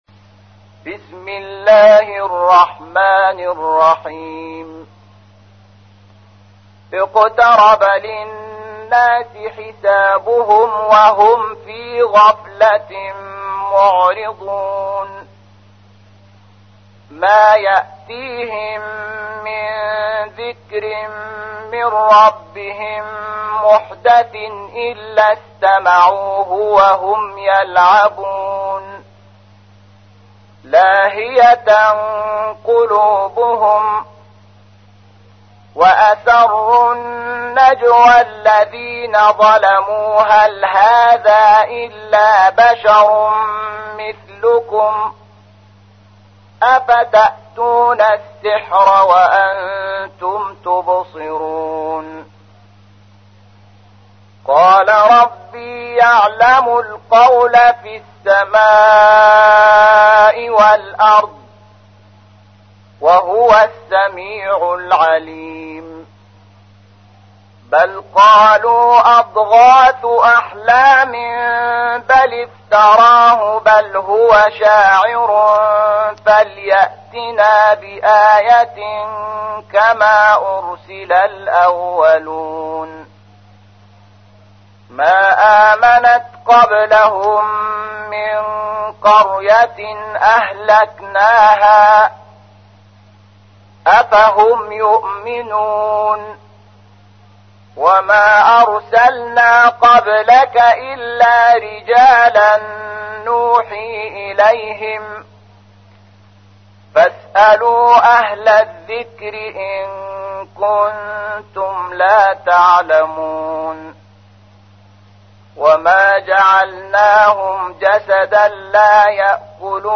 تحميل : 21. سورة الأنبياء / القارئ شحات محمد انور / القرآن الكريم / موقع يا حسين